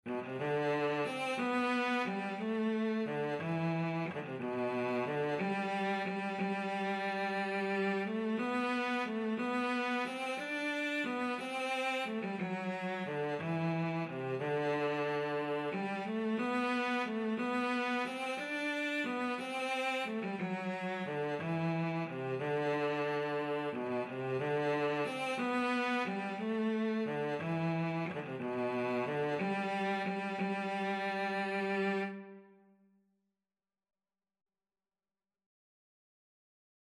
3/4 (View more 3/4 Music)
B3-D5
G major (Sounding Pitch) (View more G major Music for Cello )
Cello  (View more Easy Cello Music)
Traditional (View more Traditional Cello Music)